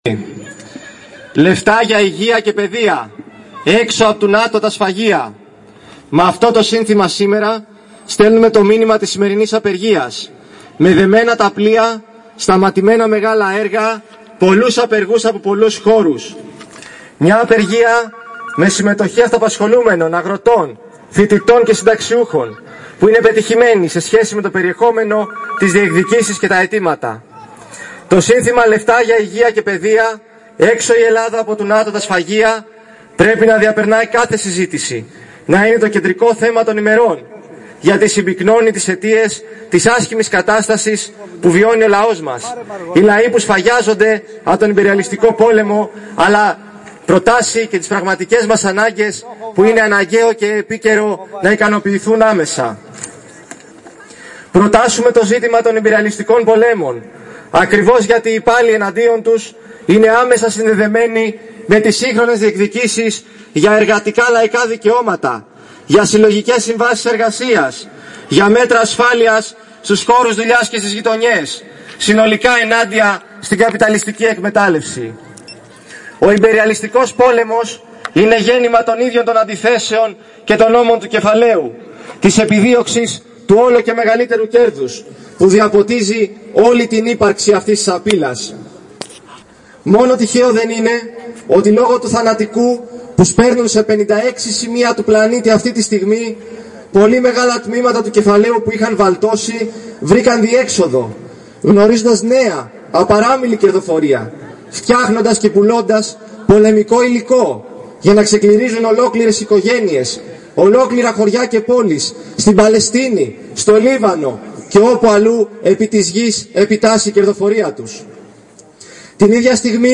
Πανελλαδική απεργία σήμερα, με  συγκέντρωση στην Πλατεία Βαλλιάνου, με  ομιλίες  και πορεία